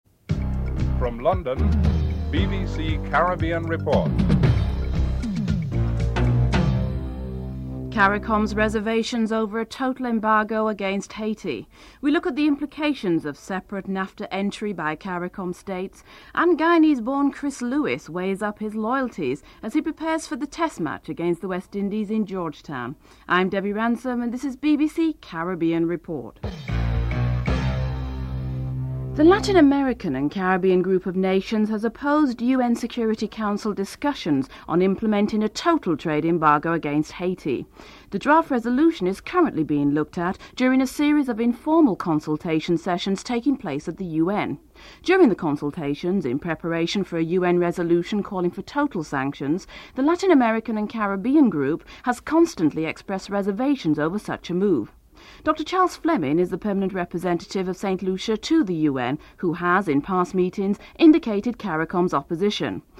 10. Wrap up and Theme music (14:48-15:02)